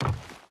Wood Run 4.ogg